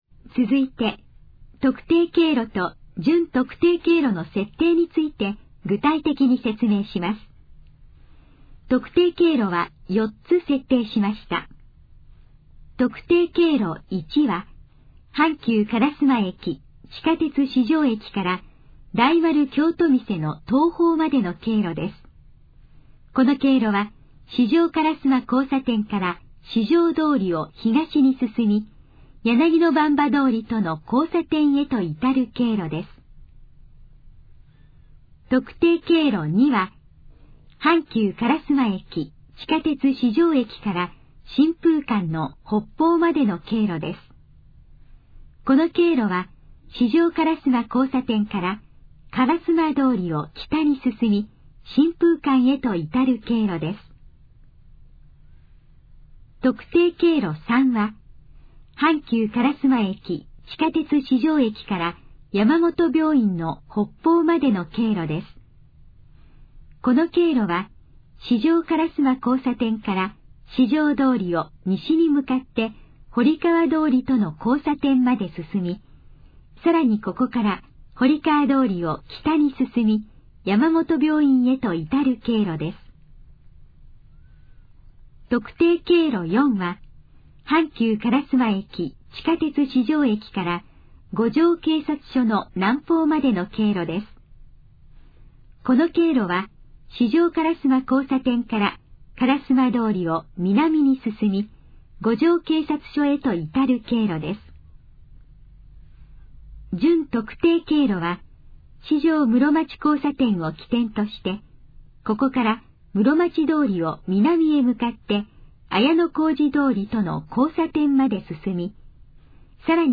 以下の項目の要約を音声で読み上げます。
ナレーション再生 約279KB